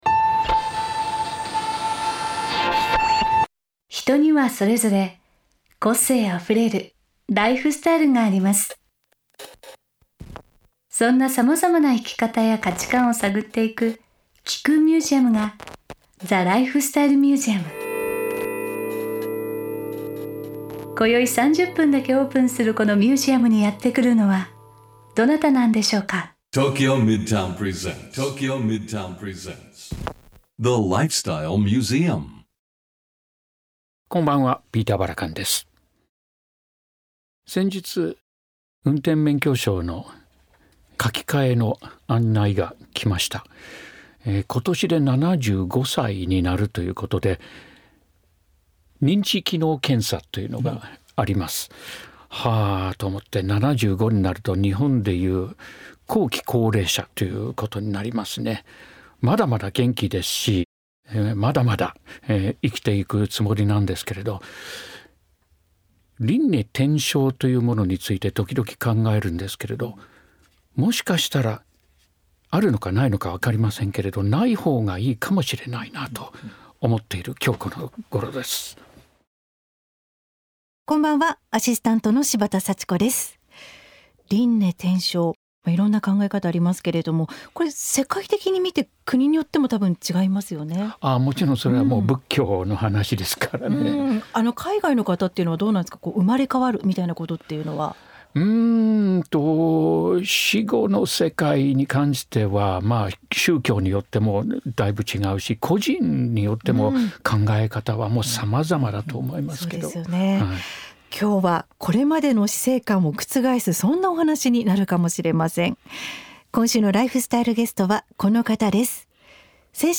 3月27日OAのゲストは、精神科医の和田秀樹さんです。